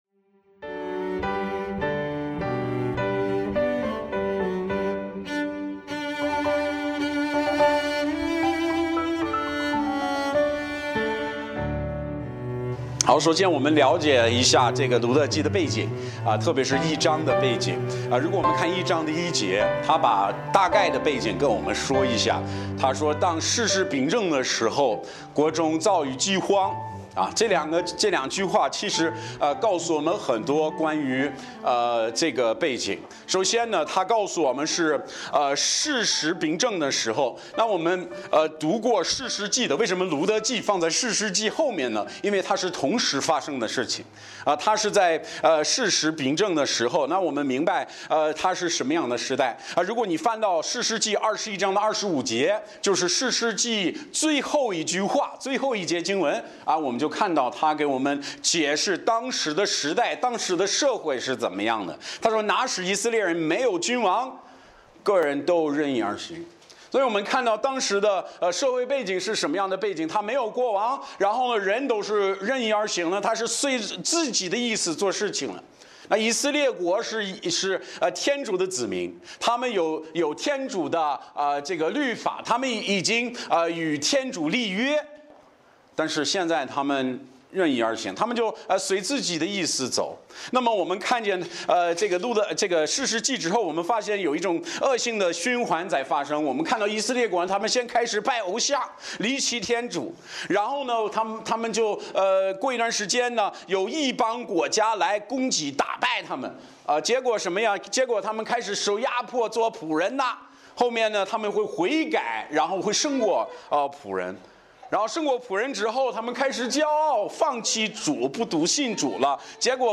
Bible Text: 路得记1：1-22 | 讲道者